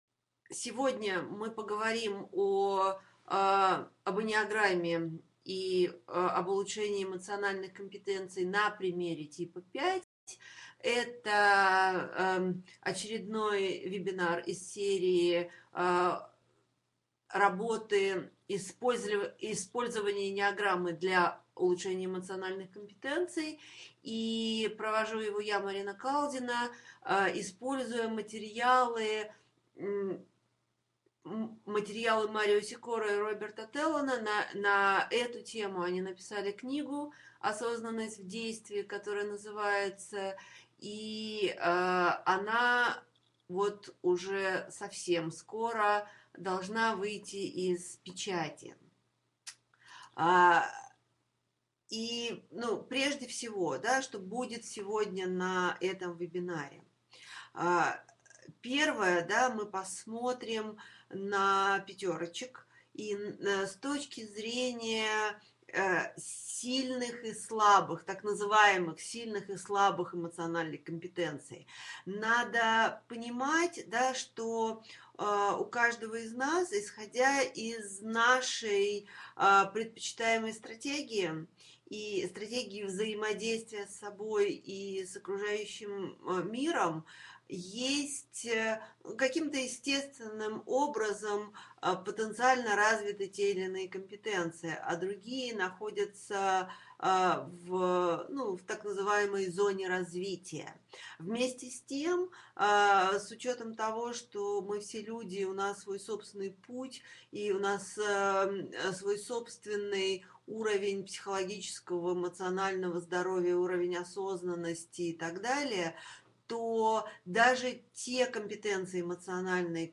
Аудиокнига Осознанность в действии. Тип 5 | Библиотека аудиокниг